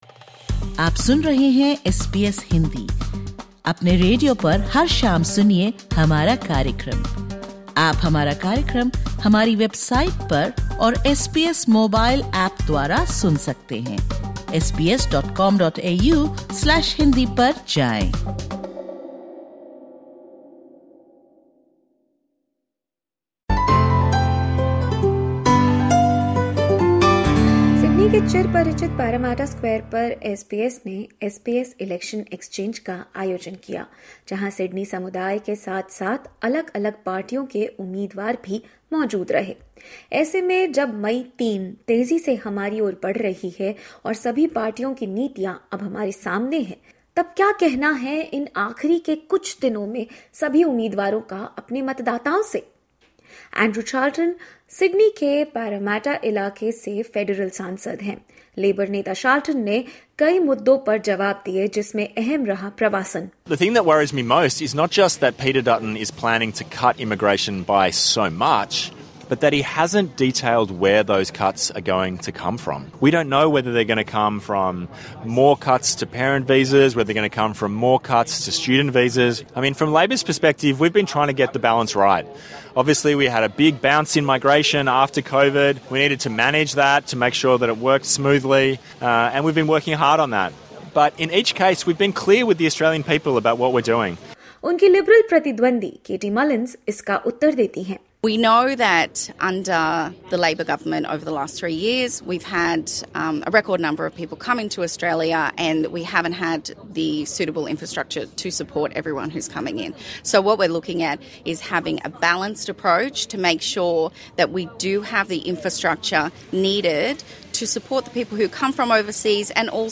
सिडनी के प्रतिष्ठित पैरामैटा स्क्वायर में एसबीएस द्वारा आयोजित 'इलेक्शन एक्सचेंज' में विभिन्न राजनीतिक दलों के उम्मीदवारों ने समुदाय के सामने अपनी अंतिम अपील रखी।